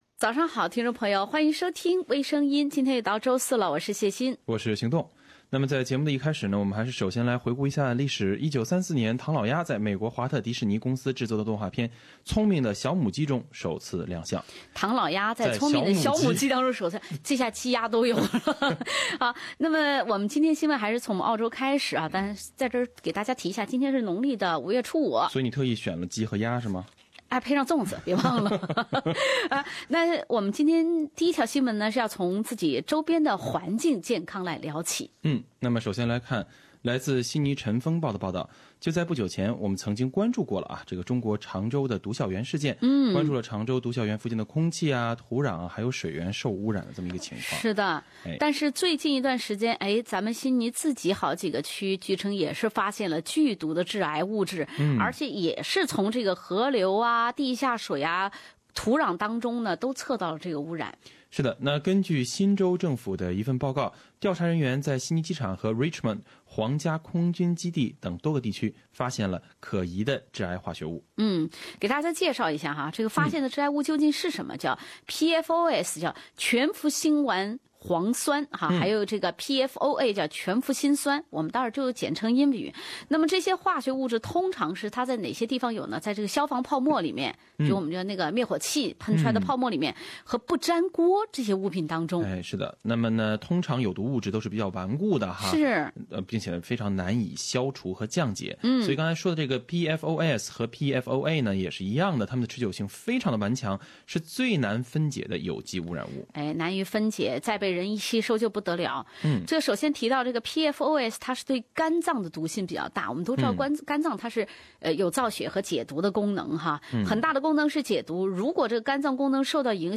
另類輕松的播報方式，深入淺出的辛辣點評；包羅萬象的最新資訊；傾聽全球微聲音。